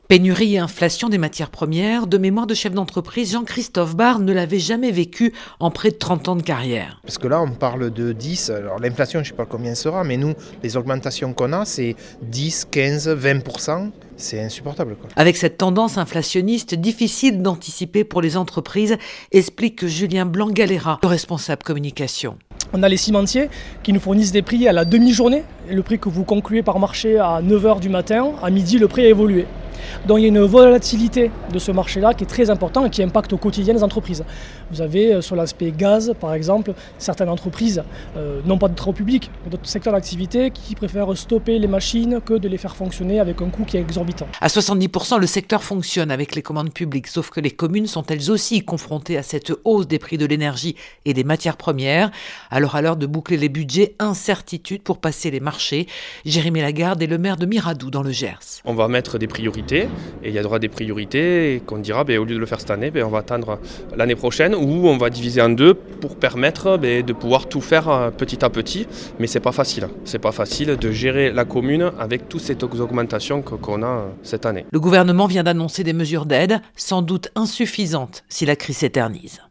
enquête